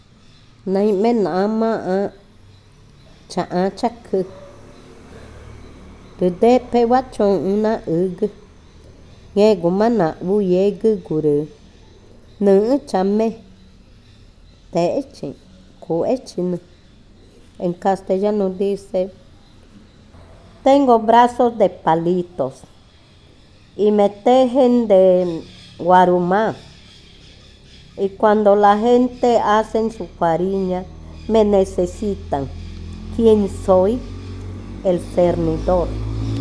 Adivinanza 21. Cernidor
Cushillococha